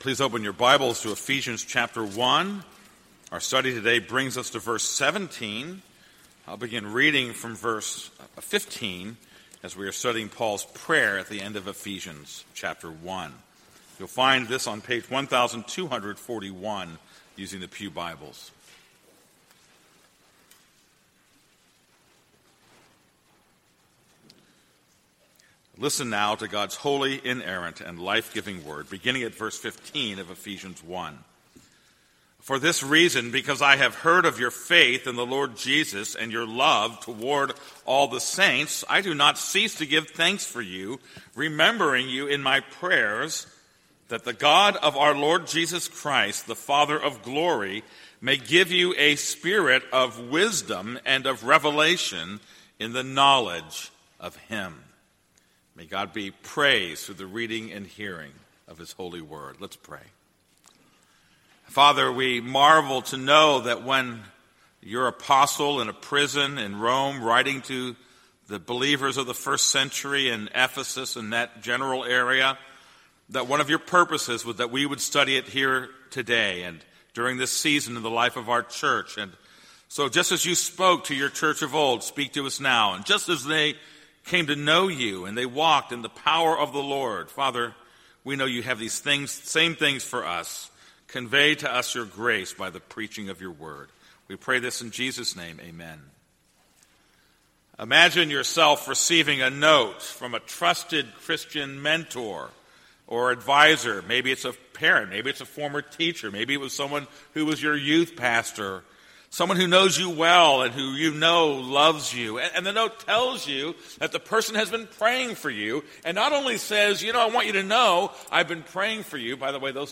This is a sermon on Ephesians 1:17.